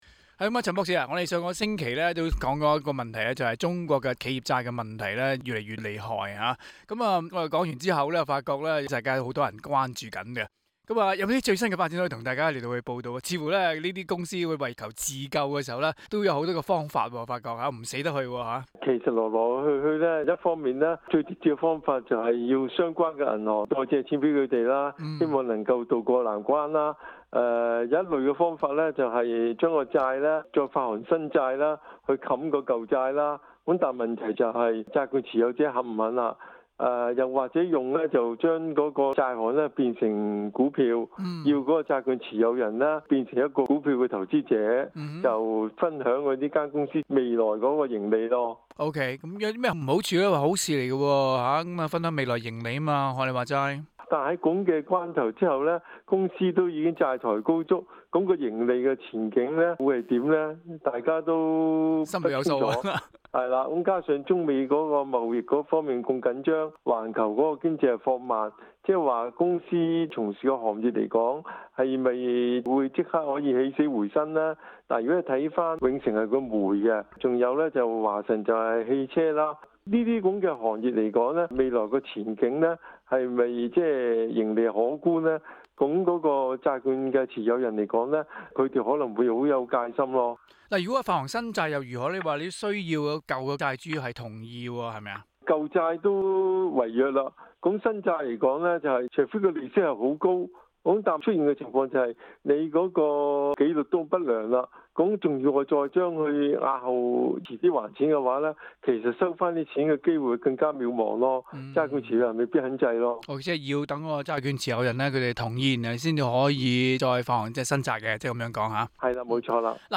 Source: Pxfuel SBS广东话播客 View Podcast Series Follow and Subscribe Apple Podcasts YouTube Spotify Download (17.75MB) Download the SBS Audio app Available on iOS and Android 最近受债务纤身的中国国企频频出招自救，究竟他们有什麽招式？